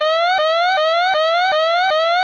GeneraterWarning.wav